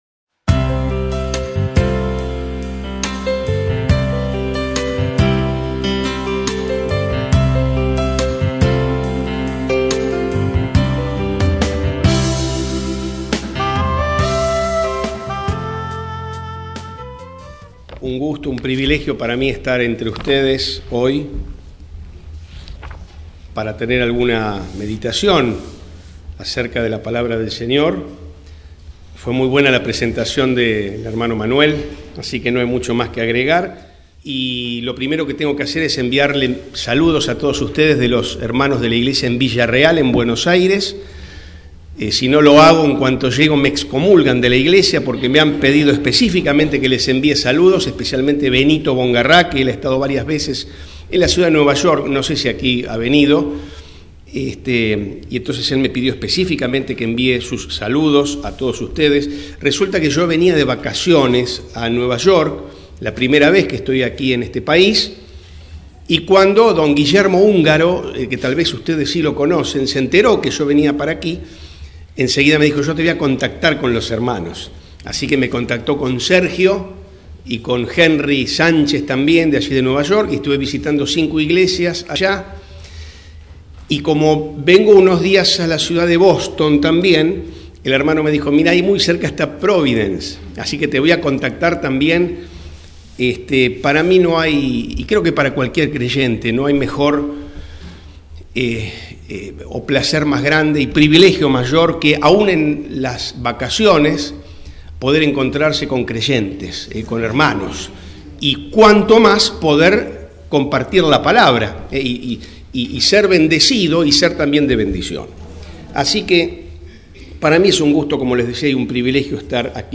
Templo Bíblico Providence